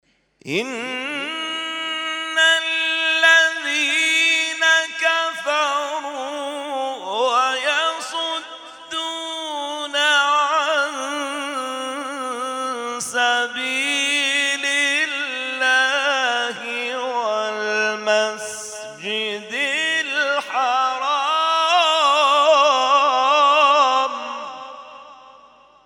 محفل انس با قرآن در آستان عبدالعظیم(ع)+ صوت